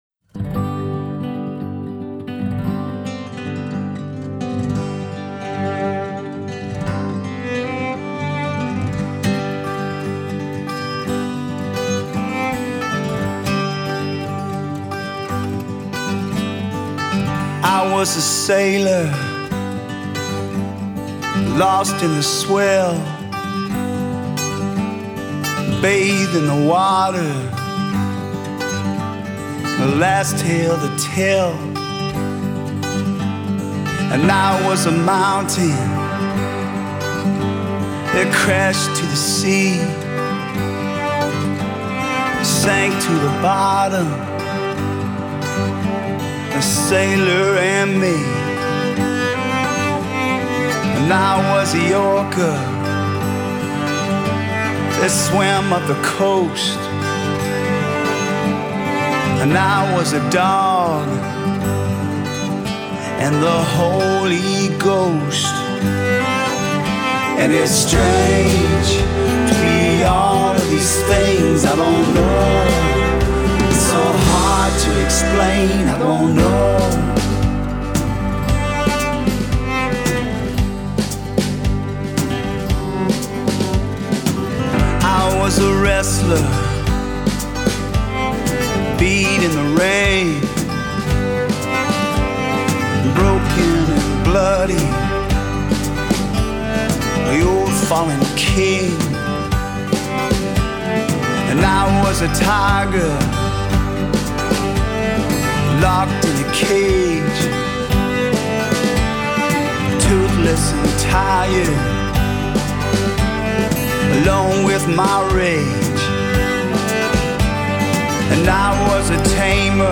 Singer, Songwriter, Guitarist